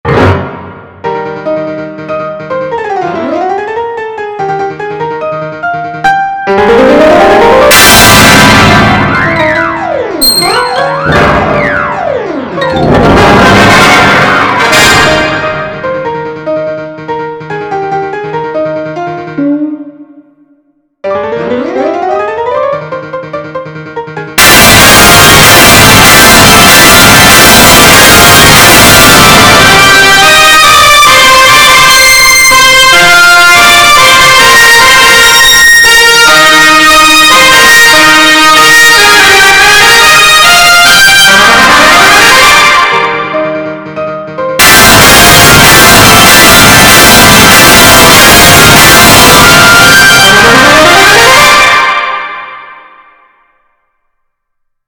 Warning: This is an actual ear rape.